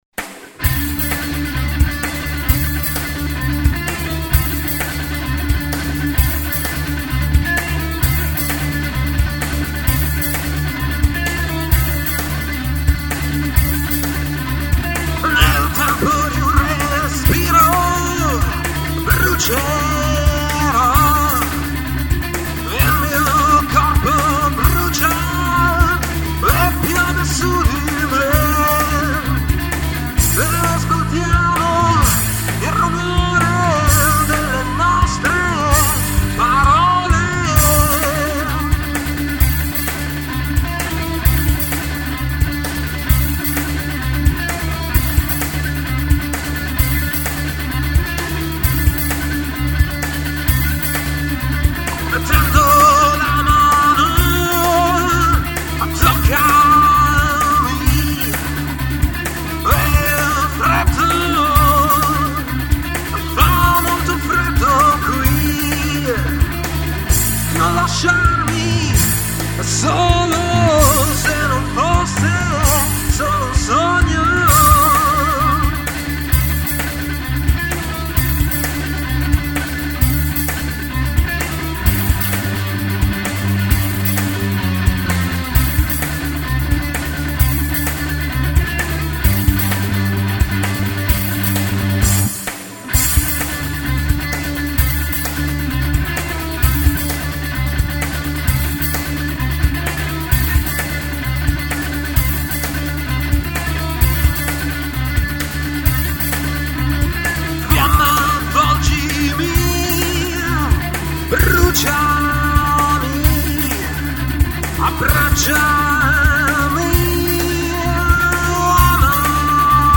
Voci e Programming
Chitarre e Basso